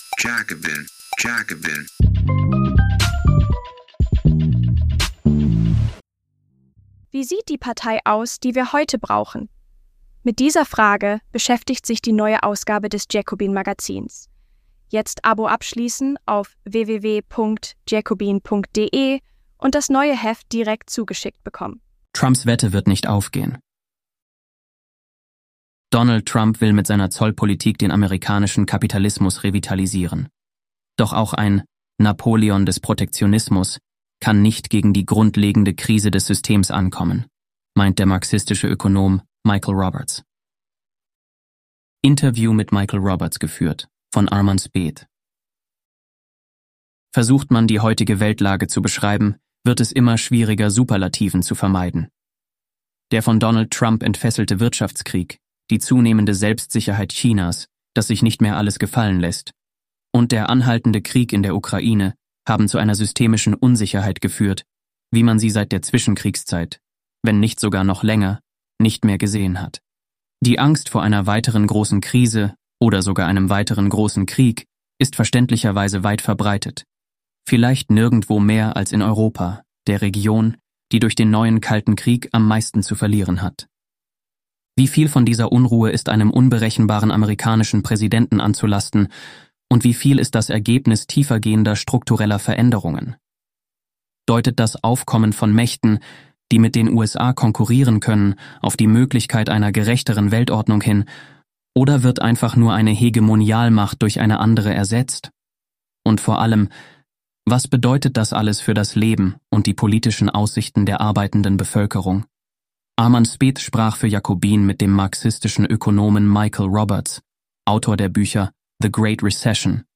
Trumps Wette wird nicht aufgehen – Interview